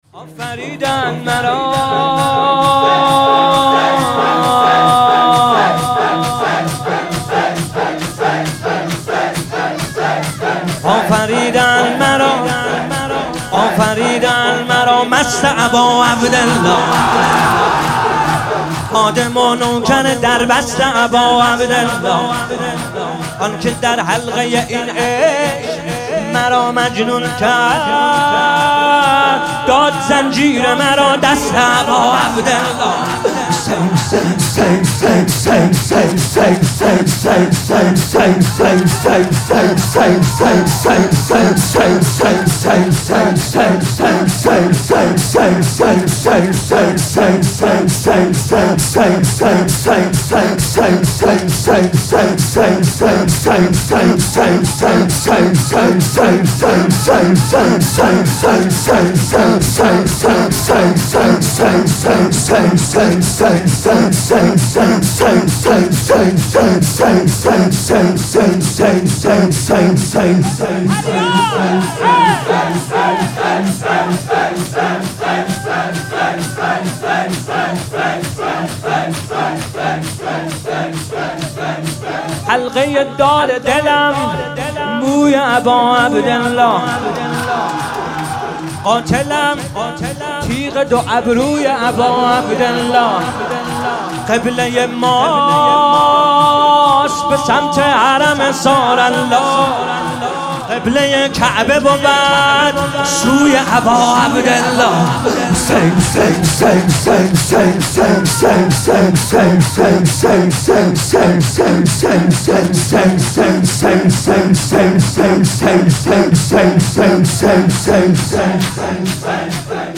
شور - آفریدند مرا مست اباعبدالله